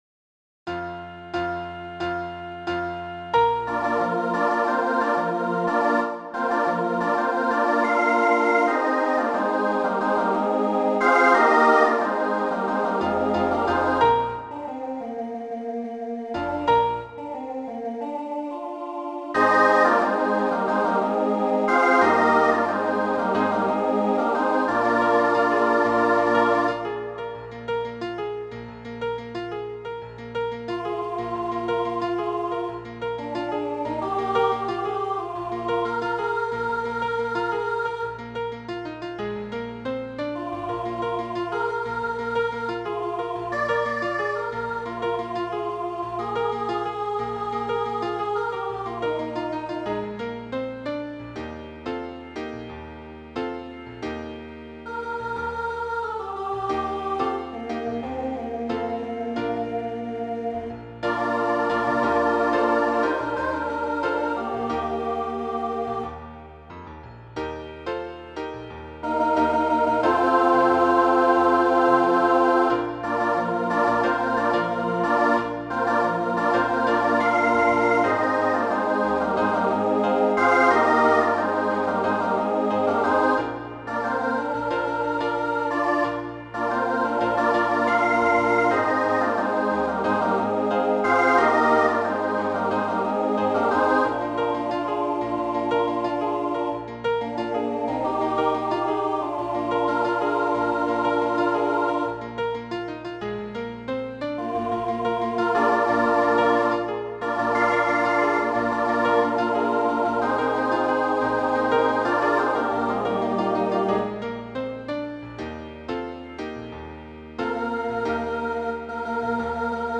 Voicing SATB Instrumental combo Genre Rock
Mid-tempo